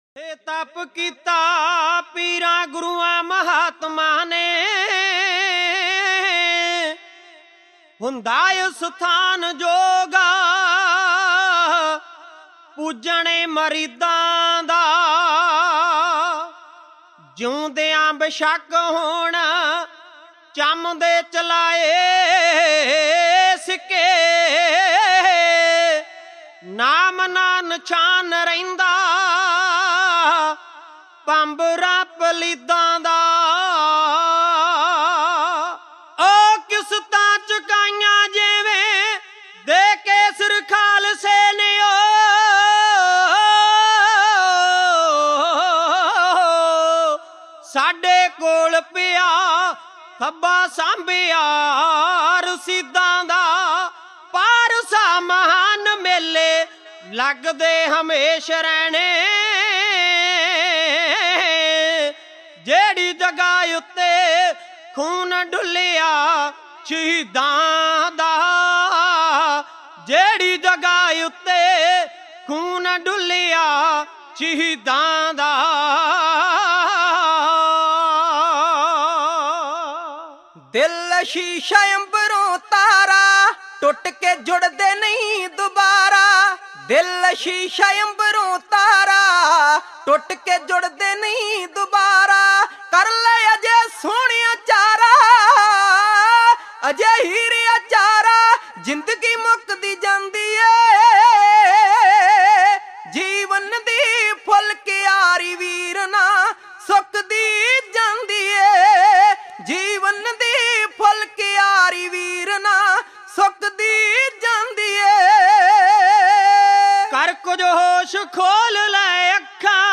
Genre: Dhadi Vaara